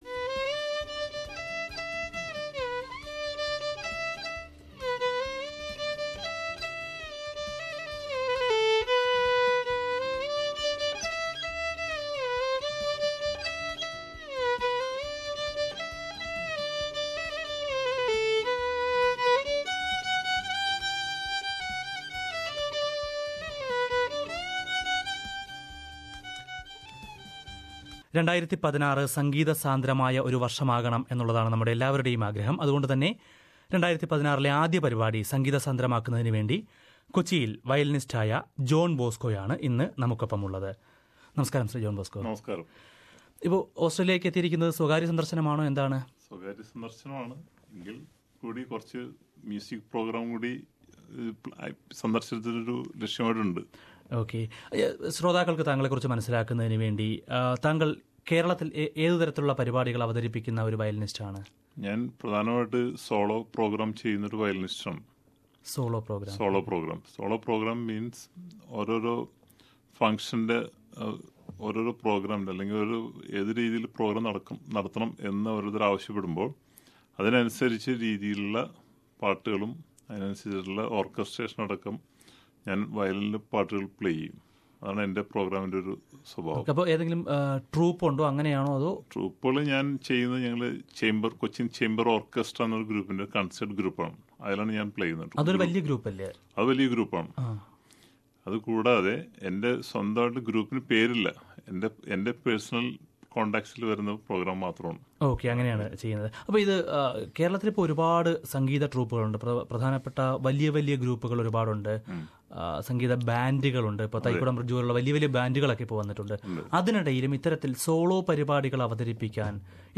In the first program of 2016, SBS Malayalam had a violinist visiting from Kerala in our studio, to give us a musical start to the year.